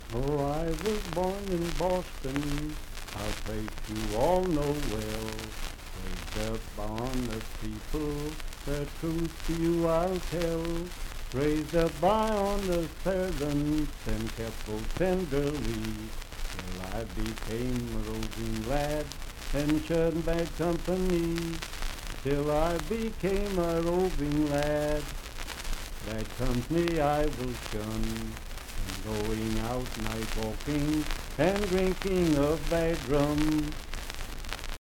Unaccompanied vocal music
Voice (sung)
Pendleton County (W. Va.), Franklin (Pendleton County, W. Va.)